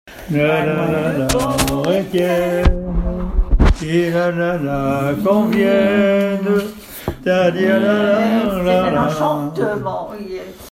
Catégorie Pièce musicale inédite